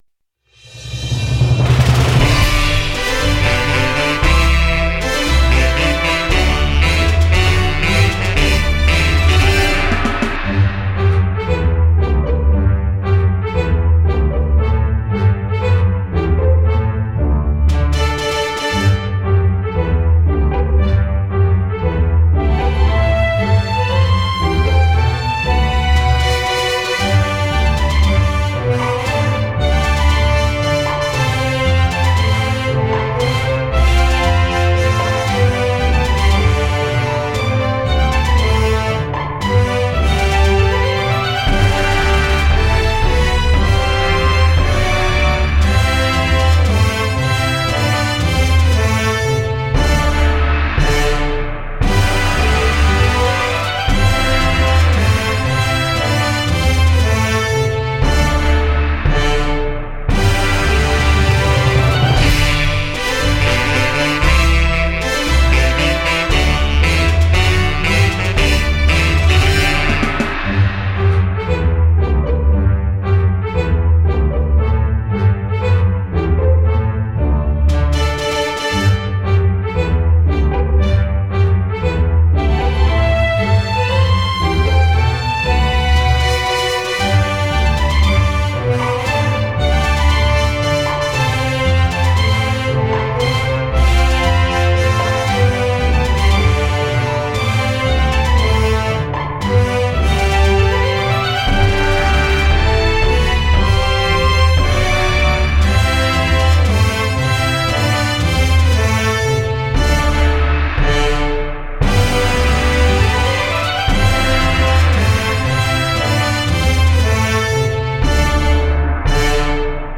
伴奏.mp3